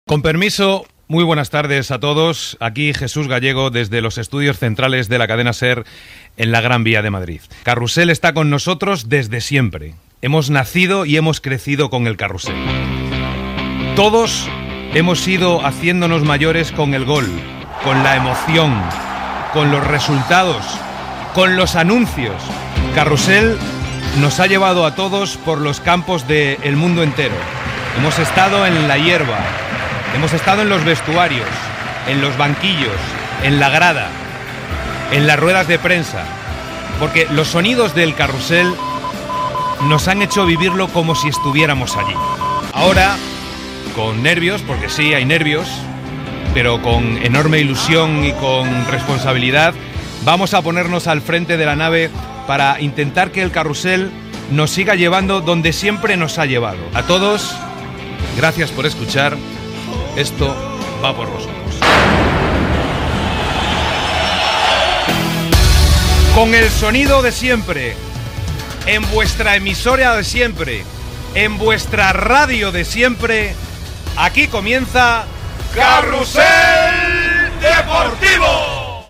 Inici del primer programa que presenta Jesús Gallego
Esportiu